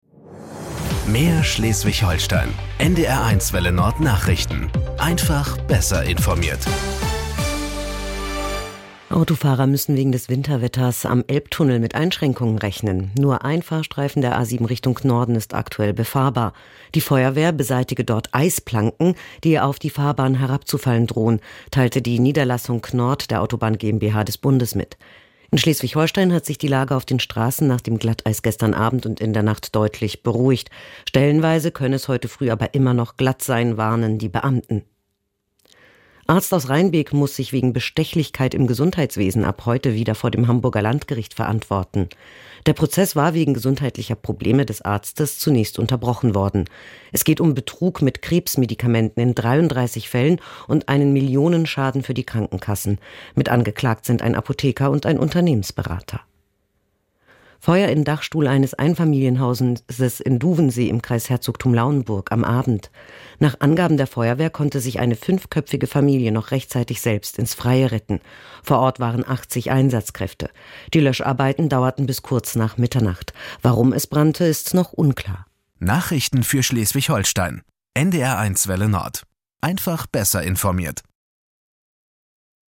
Nachrichten 08:30 Uhr - 13.01.2026